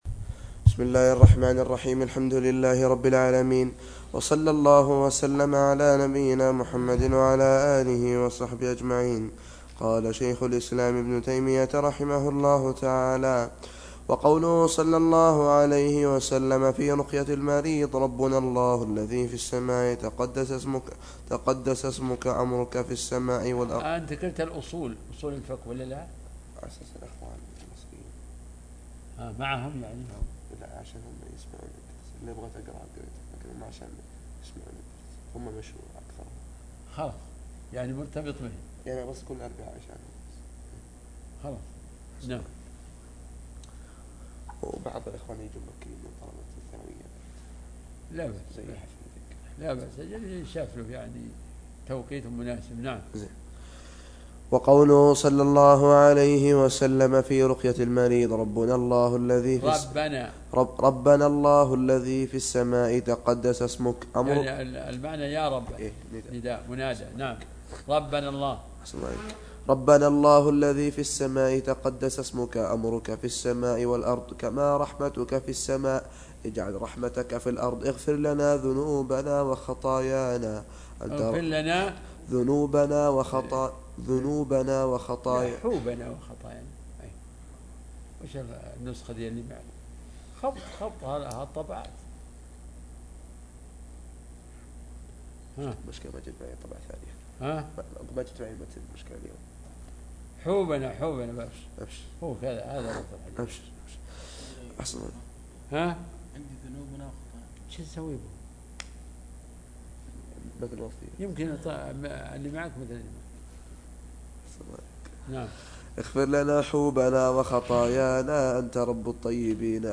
العقيدة الإسلامية      شروح كتب عقيدة